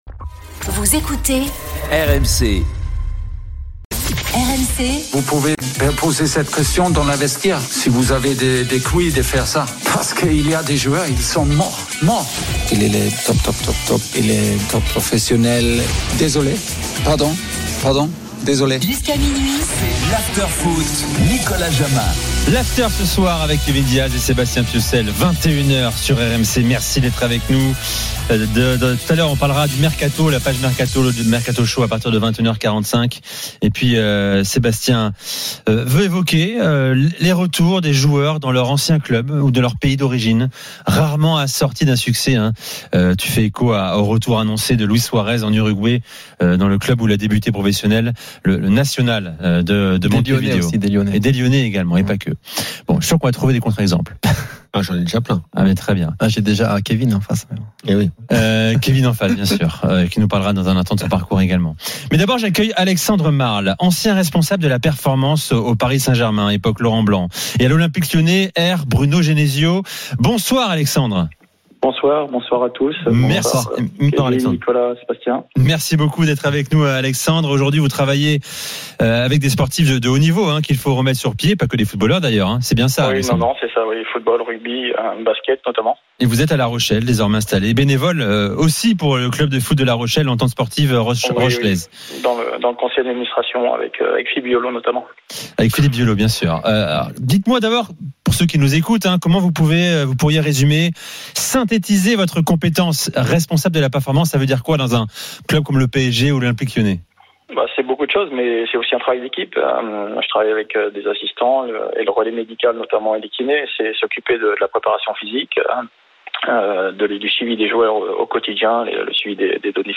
L'After foot, c'est LE show d'après-match et surtout la référence des fans de football depuis 15 ans !
RMC est une radio généraliste, essentiellement axée sur l'actualité et sur l'interactivité avec les auditeurs, dans un format 100% parlé, inédit en France.